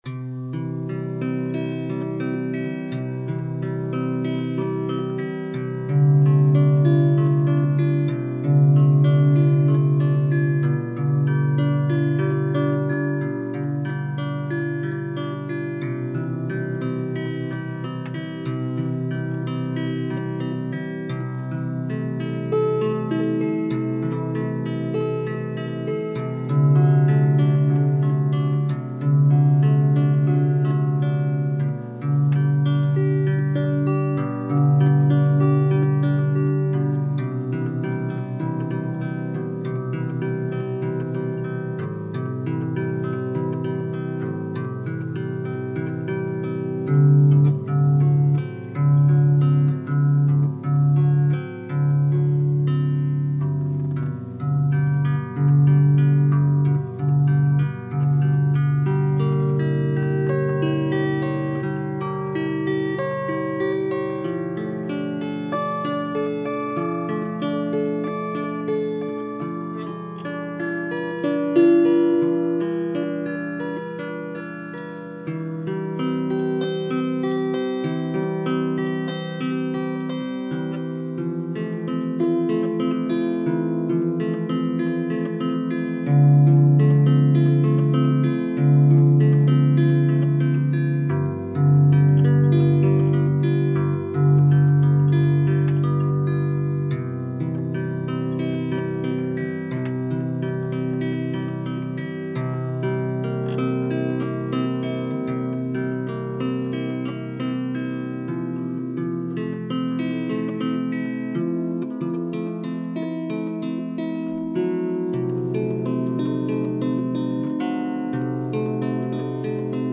ライアーの響きが持つ” ゆらぎ” が究極のリラクゼーションをもたらしてくれるでしょう。